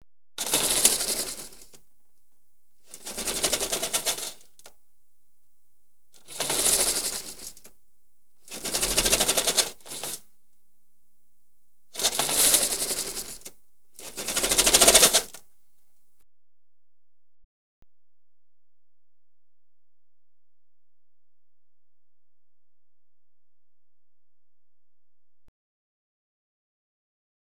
Window Shade Open And Close Sound Effect
Download a high-quality window shade open and close sound effect.
window-shade-open-and-close.wav